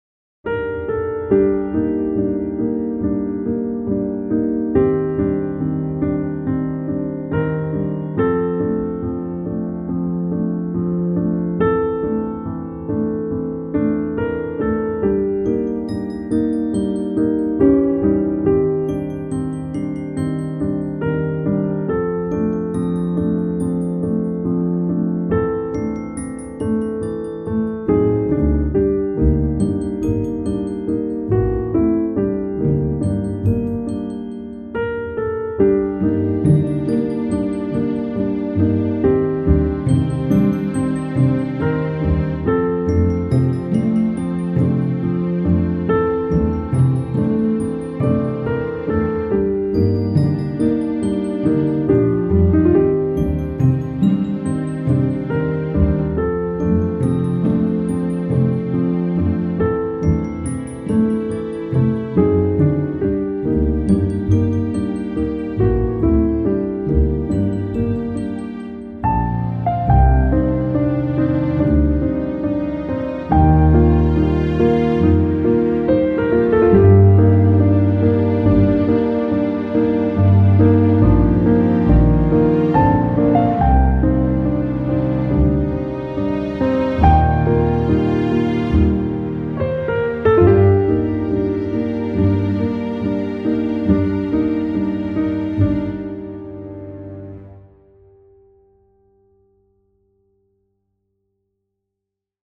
piano - calme - melodieux - melancolique - triste